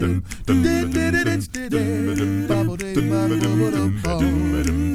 ACCAPELLA 1B.wav